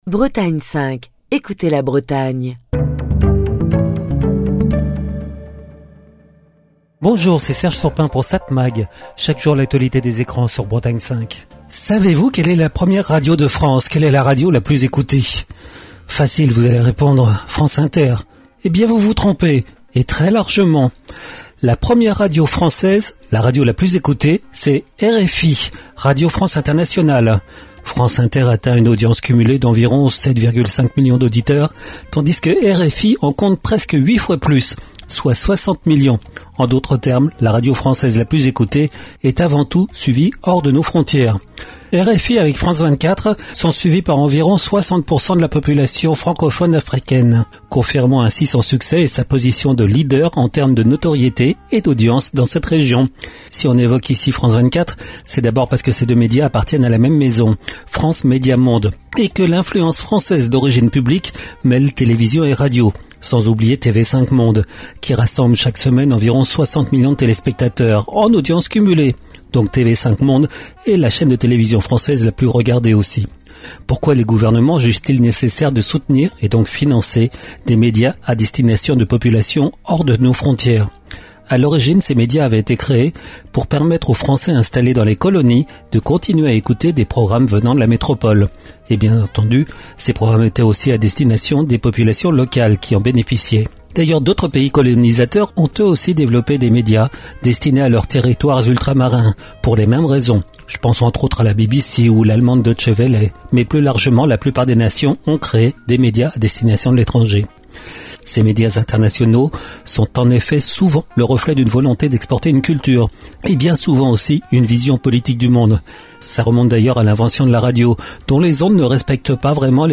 Chronique du 19 mars 2025. Savez-vous quelle est la radio française la plus écoutée ?